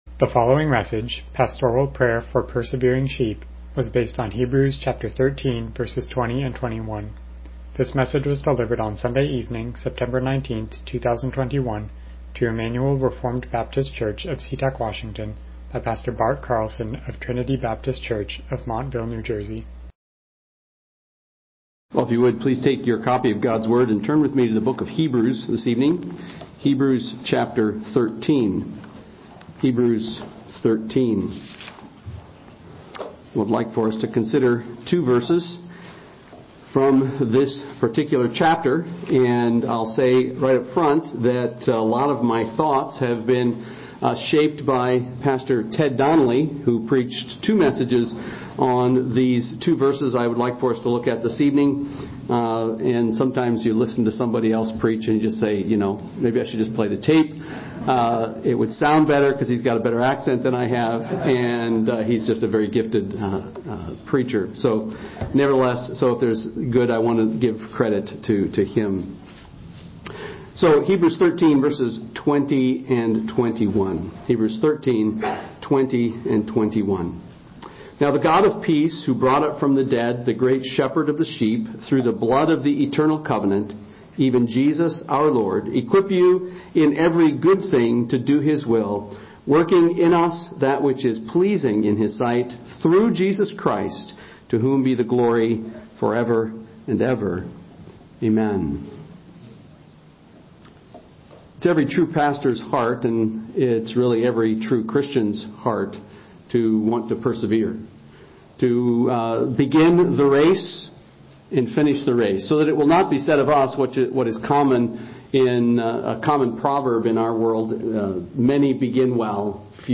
Hebrews 13:20-21 Service Type: Evening Worship « Hallelujah